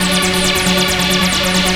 ACID LOOP00R.wav